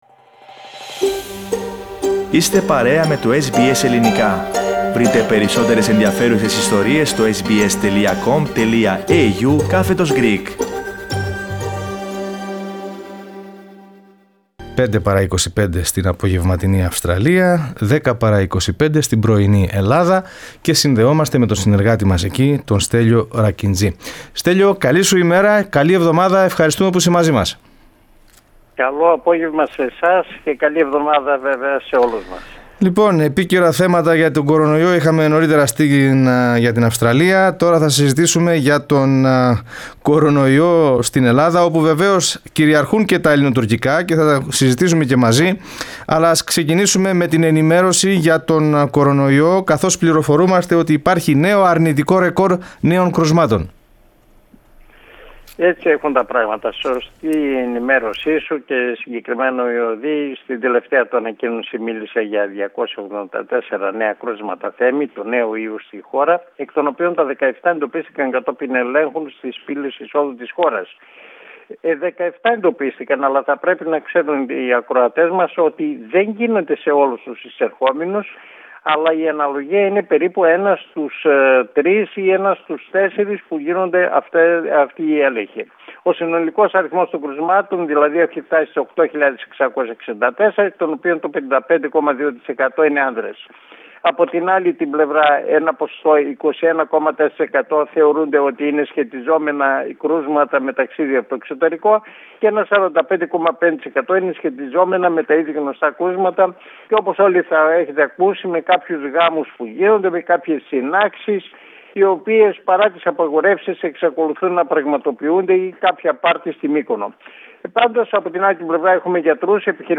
Οι τελευταίες εξελίξεις στα μέτωπα του κορωνοϊού, της οικονομίας και των ελληνοτουρκικών στην εβδομαδιαία ανταπόκριση από την Ελλάδα.